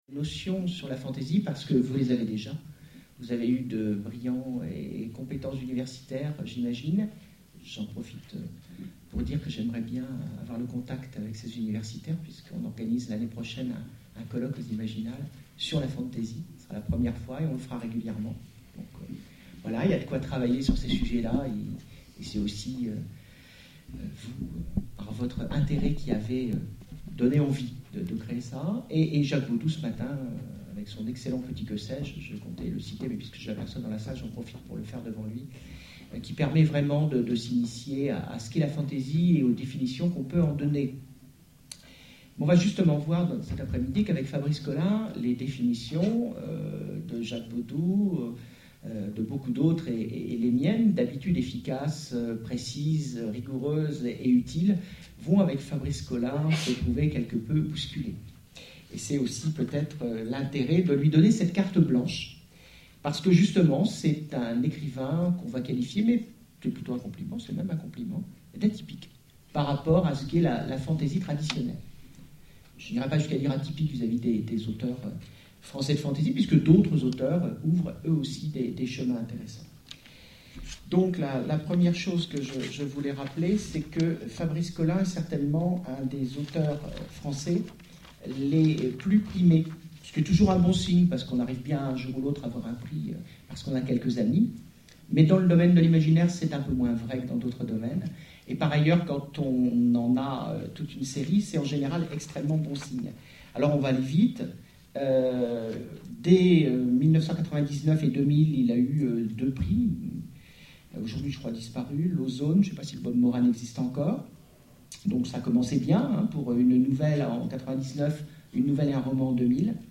En mars dernier, l’association organisait une journée d’étude autour de la fantasy.